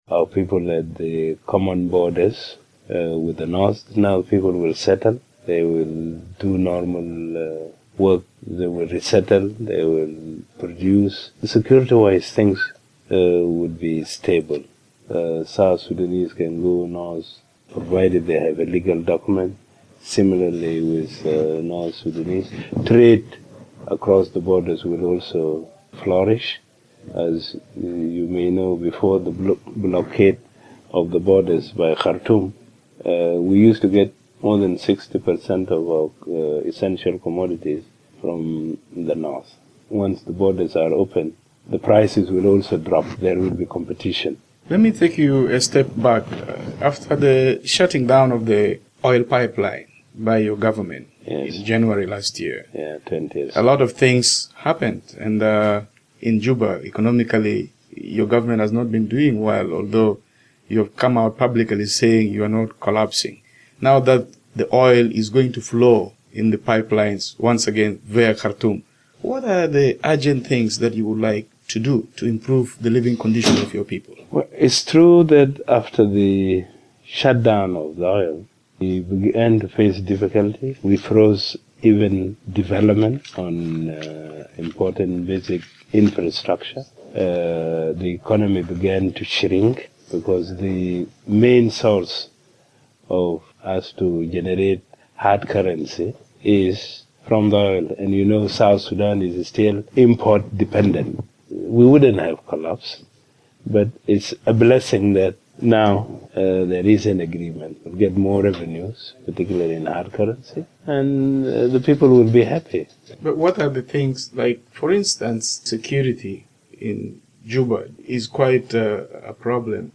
VOA spoke to Vice President Riek Machar in New York, shortly after he addressed the United Nations General Assembly.